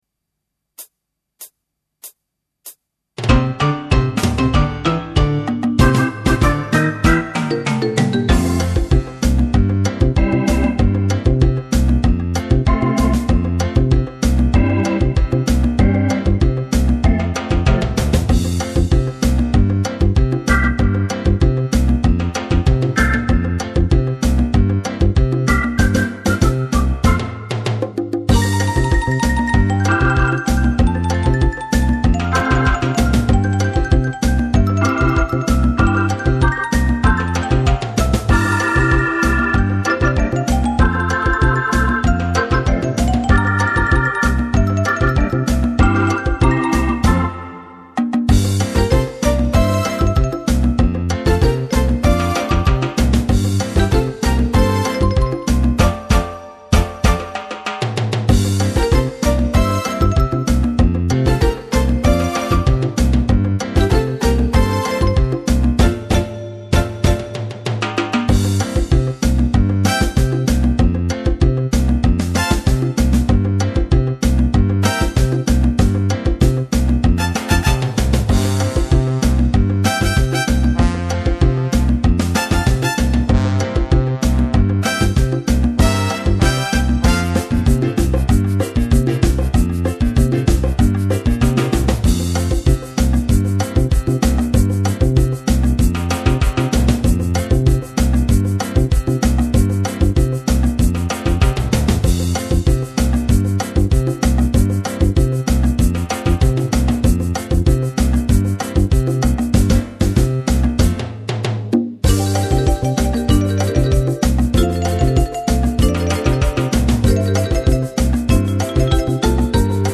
Pianoforte, orchestra